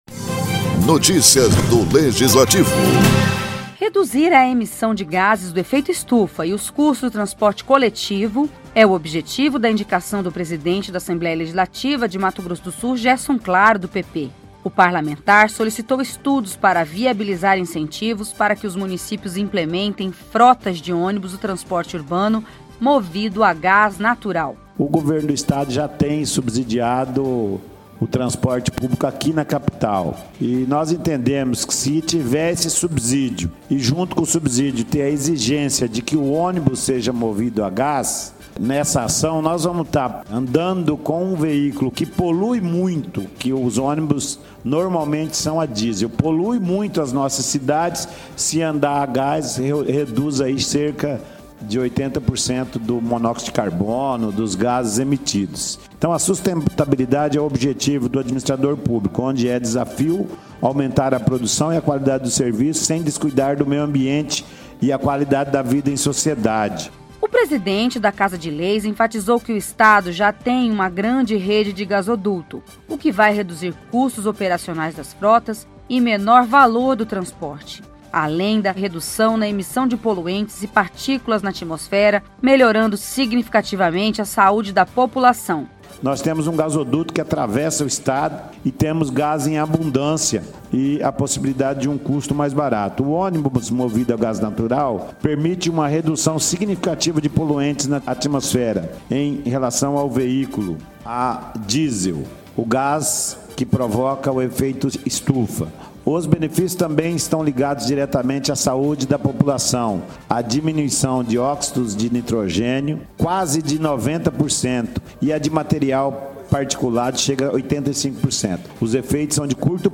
O assunto foi tema de debate O na tribuna da Assembleia Legislativa do Estado (ALEMS), durante sessão ordinária.